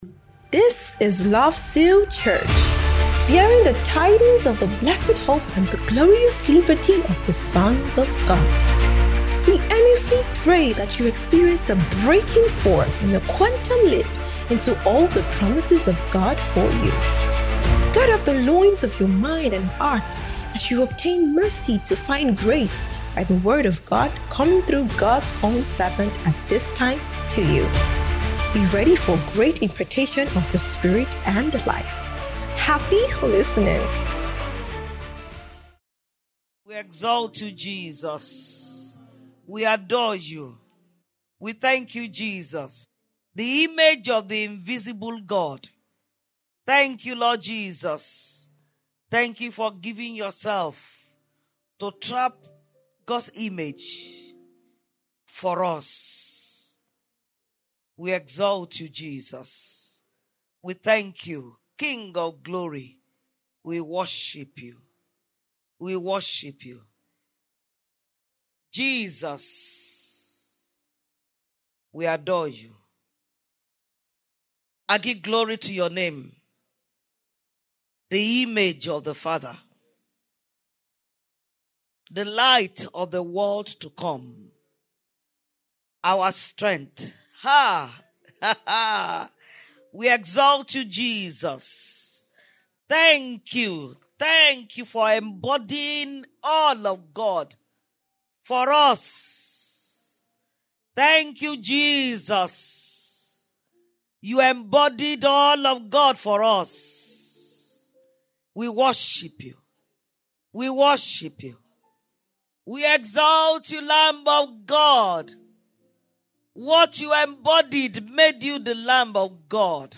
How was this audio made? Spirit Life Reign 2025 - Day 3